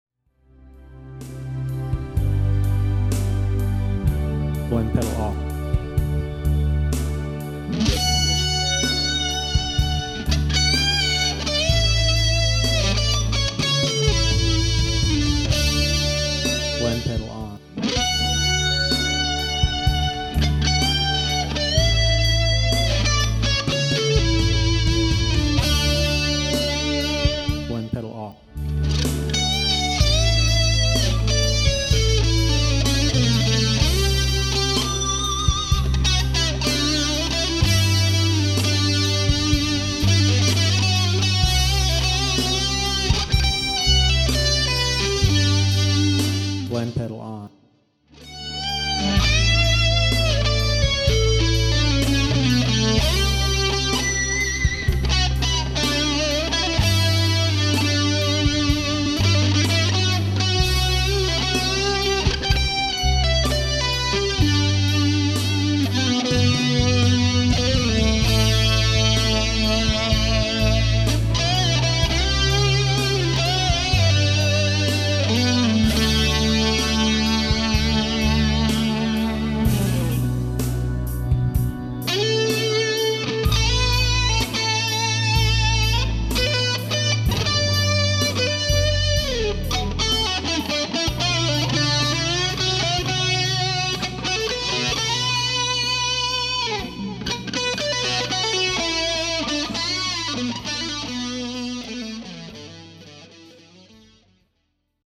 Very warm and smooth.
The Earth Drive works as a smooth blend pedal for the Muff, and works best following it in the signal chain.
Here is one of my harsher Ram’s head Big Muffs being tamed by the Earth Drive.
EARTH_DRIVE_Big_Muff_Blend_Demo2_SSL5.mp3